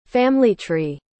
• Family: Divida em duas partes – “FAM” (com som de “a” aberto) e “uh-lee”.
• Tree: Pronuncie “trii”, com o som do “i” bem prolongado.